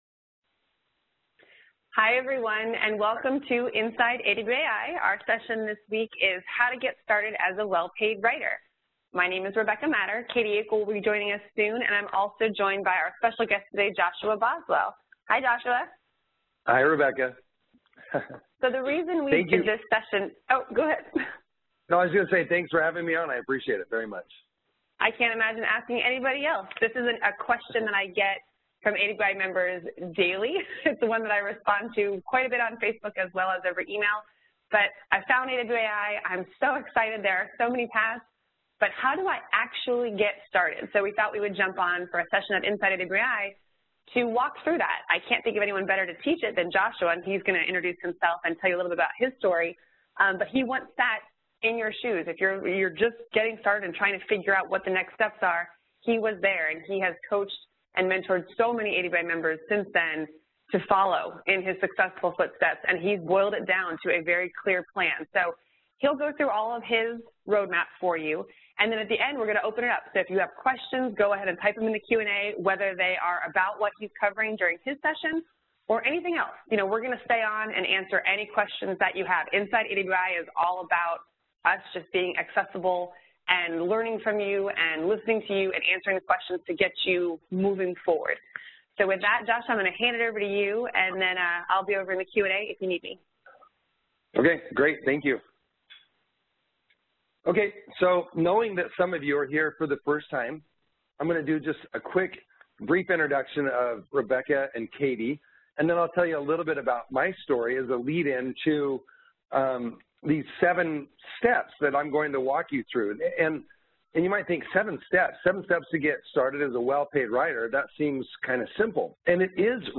Inside AWAI Webinar and Q&A: How to Get Started as a Well-Paid Writer (With Answers on Finding Copywriter Jobs)